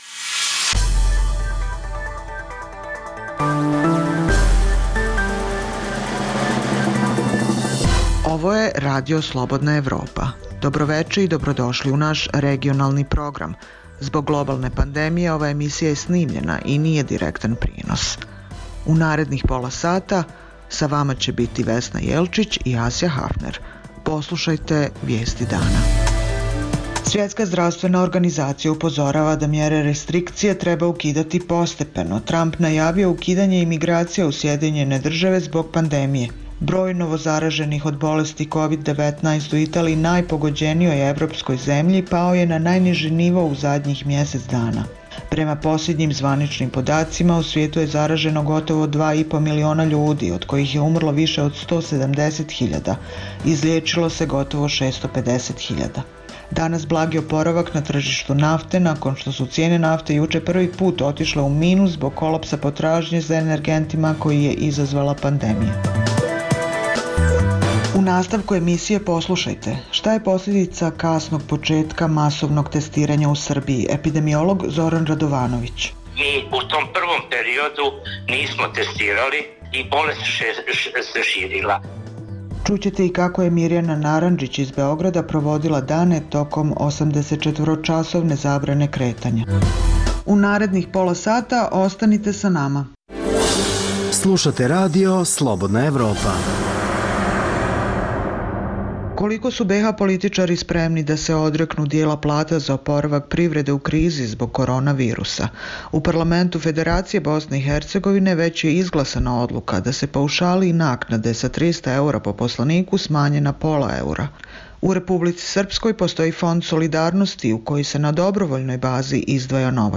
Zbog globalne pandemije, ova je emisija unapred snimljena i nije direktan prenos Svetska zdravstvena organizacija upozorava da mere restrikcije treba ukidati postepeno, Tramp najavio ukidanje imigracija u SAD zbog pandemije. Prema poslednjim zvaničnim podacima u svijetu je zaraženo gotovo 2,5 miliona ljudi, od kojih je umrlo više od 170.000, a izlečilo se gotovo 650.000. Danas blagi oporavak na tržištu nafte nakon što su cene nafte juče prvi put otišle u minus zbog kolapsa potražnje za energentima koji je izazvala pandemija